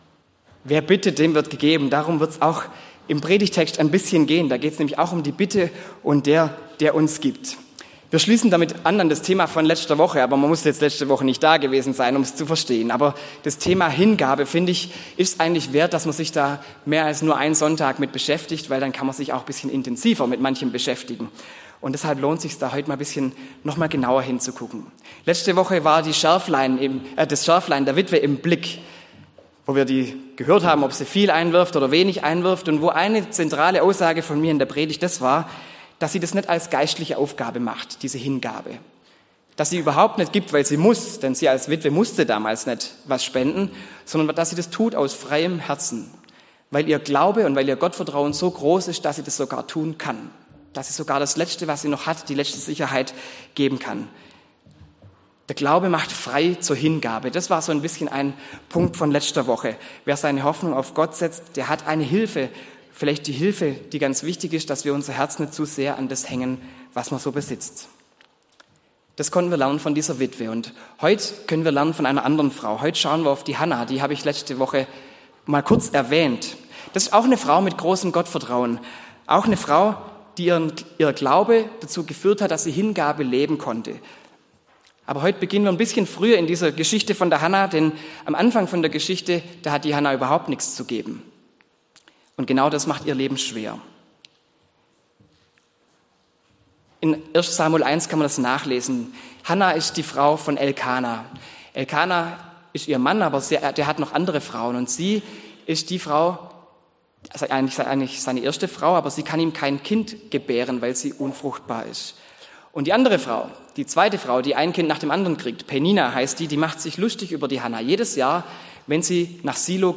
Zweite Predigt zur Themenreihe "Hingabe"